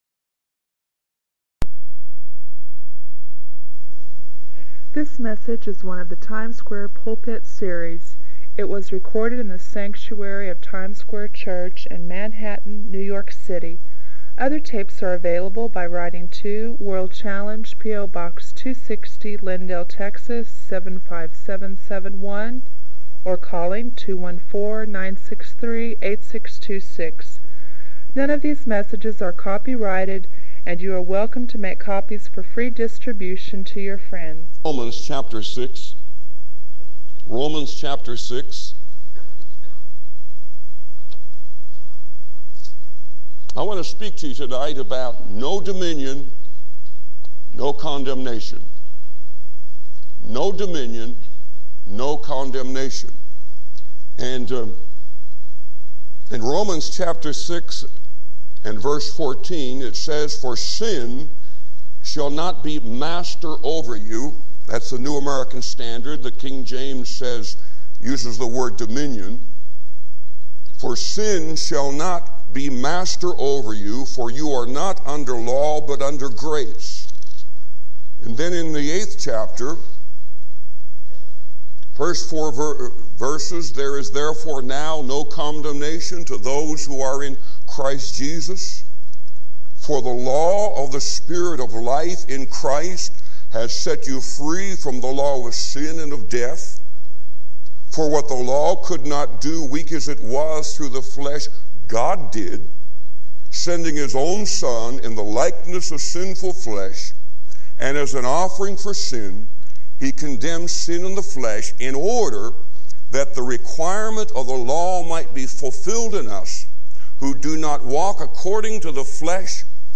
This message encourages Christians to live victorious lives empowered by the Spirit, grounded in humility and faith.